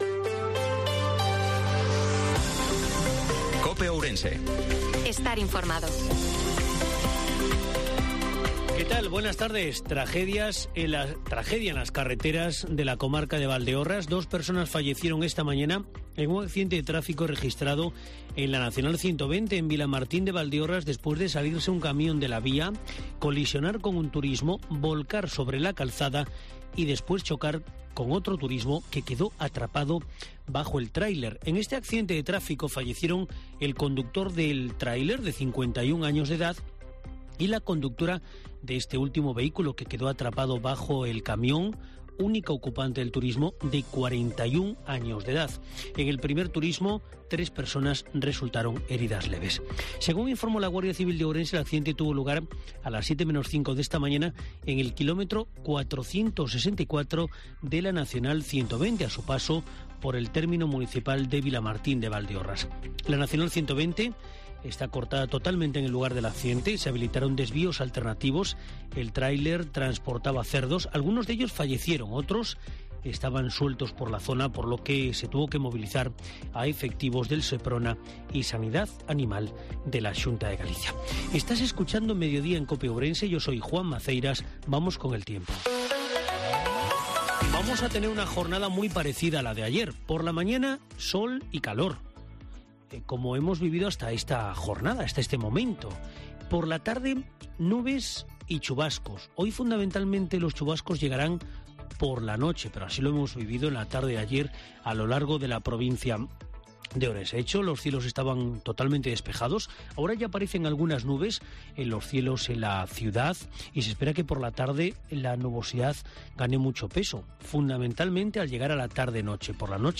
INFORMATIVO MEDIODIA COPE OURENSE-25/05/2023